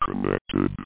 Amiga 8-bit Sampled Voice
connected.mp3